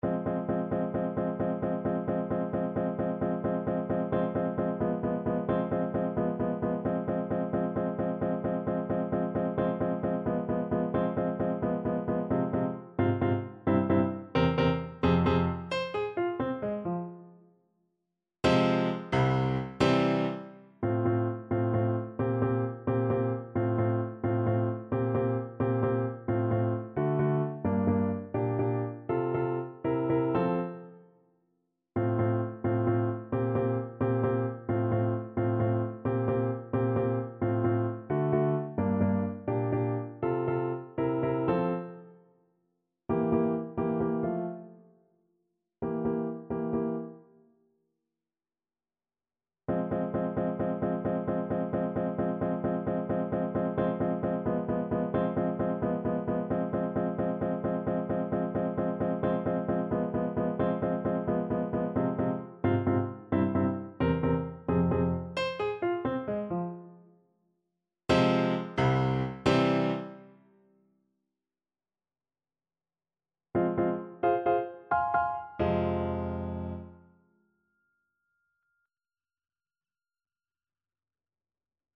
Play (or use space bar on your keyboard) Pause Music Playalong - Piano Accompaniment Playalong Band Accompaniment not yet available transpose reset tempo print settings full screen
F minor (Sounding Pitch) G minor (Trumpet in Bb) (View more F minor Music for Trumpet )
3/8 (View more 3/8 Music)
Allegro con brio (.=104) .=88 (View more music marked Allegro)
Classical (View more Classical Trumpet Music)